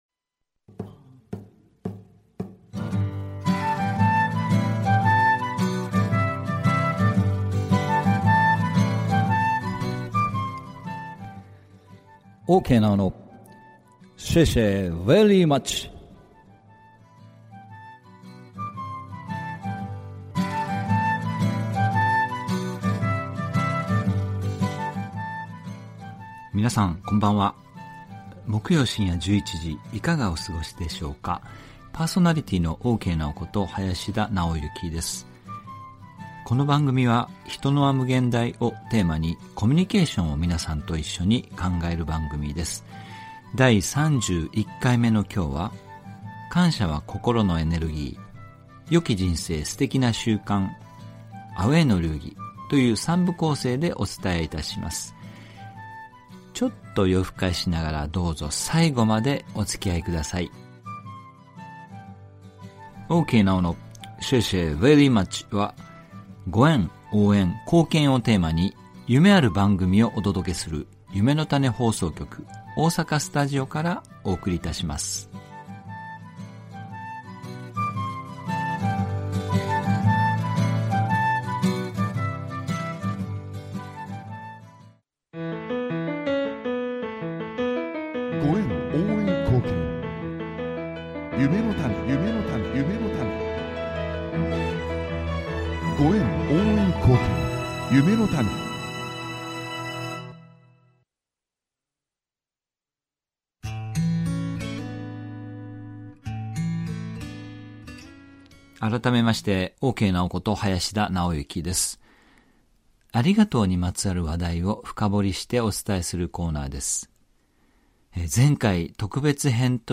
2020年8月 第31回（母へのインタビュー）